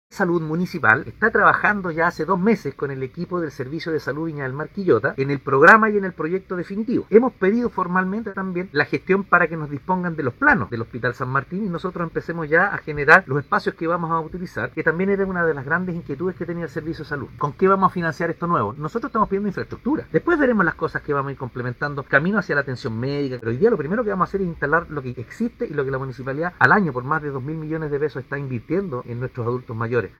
01-ALCALDE-Trabajo-tecnico-y-planificacion.mp3